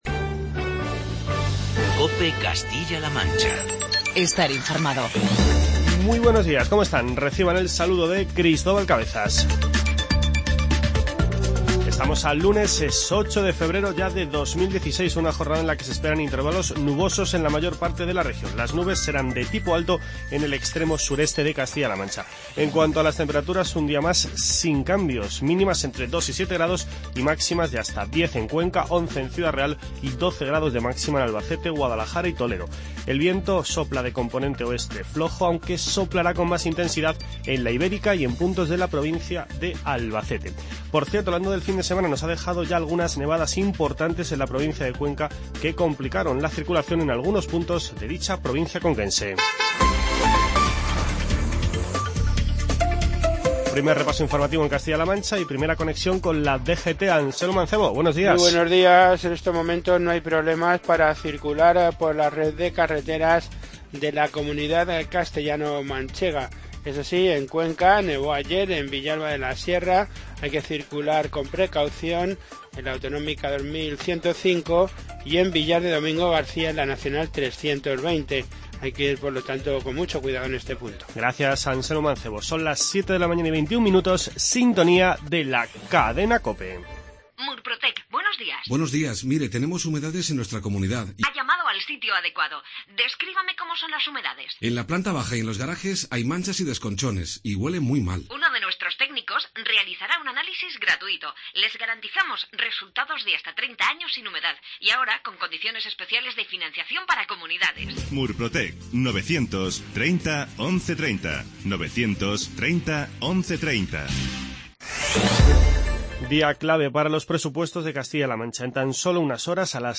Informativo regional
Emiliano García-Page y José García Molina comparecen hoy en rueda de prensa para dar a conocer los detalles de los Presupuestos de 2016.